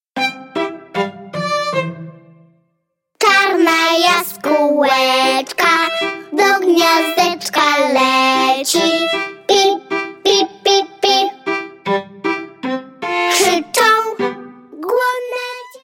pełne rymów piosenki